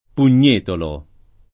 Pugnetolo [ pun’n’ % tolo ]